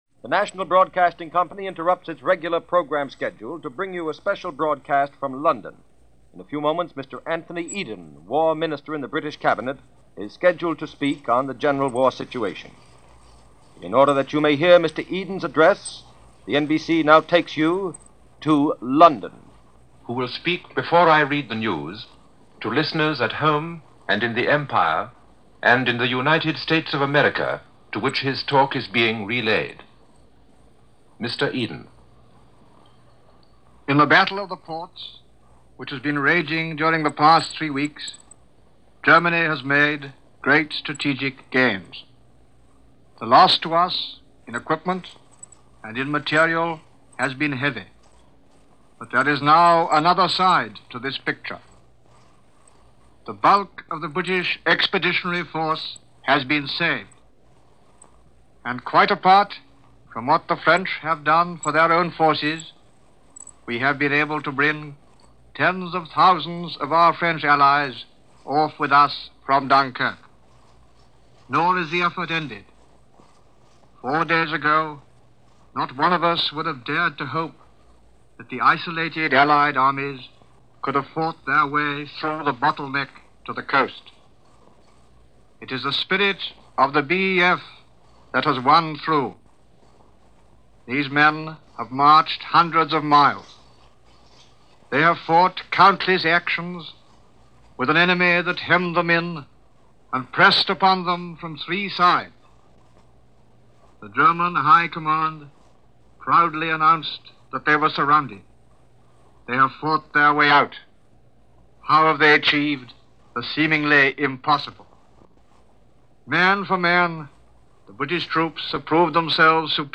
On June 2nd, as the evacuation was in it’s end stages, War Minister Anthony Eden addressed the nation (and the world) on the situation that was taking place.
Here is that broadcast, as it was relayed to the U.S. via NBC Radio on June 2, 1940.